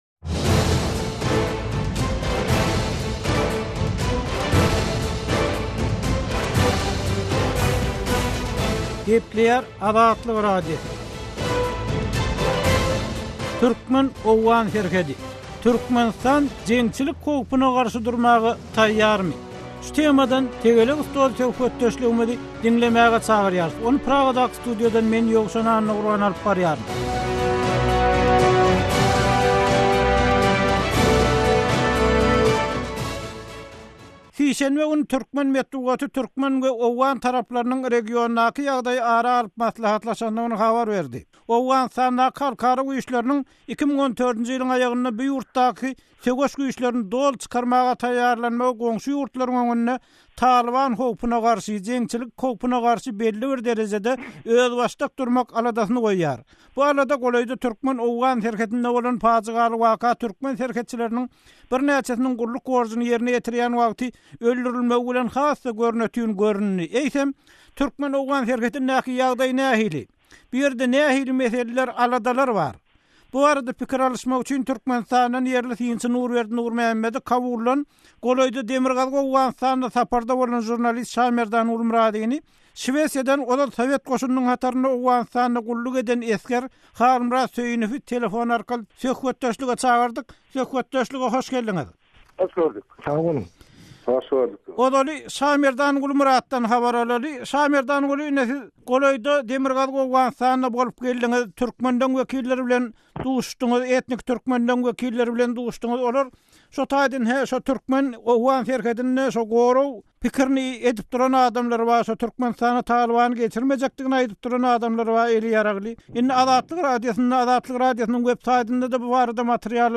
Tegelek stol: Türkmen-owgan serhedi: Berklik nämede?